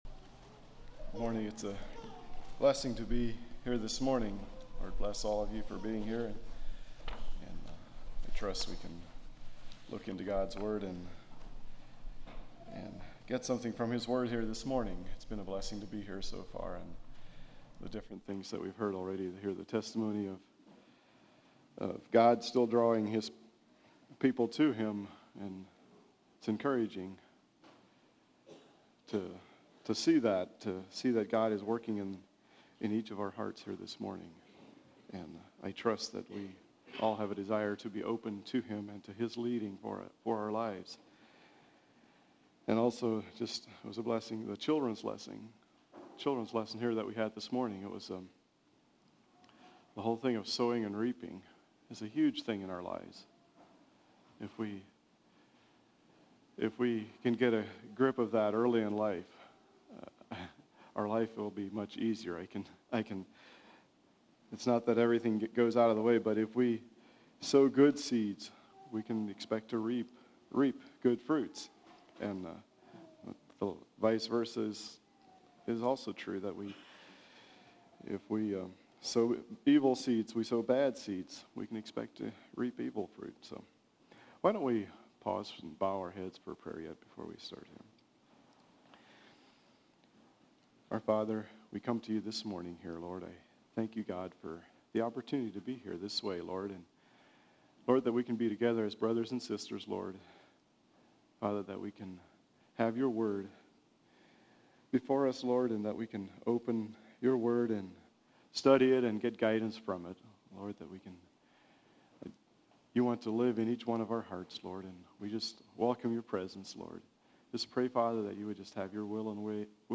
An expository sermon on James 1, being a Servant.